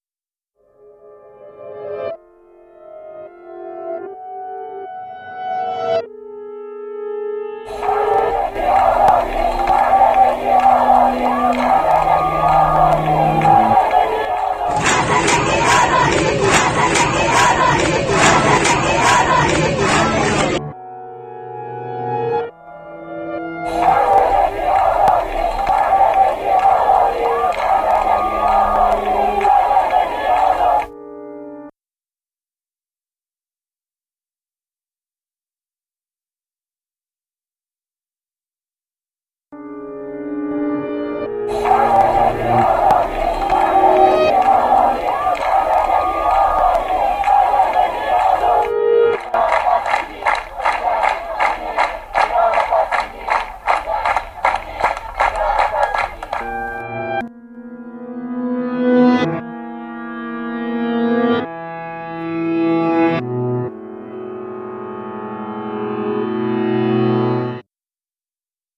for nine instrument and
recorded electronics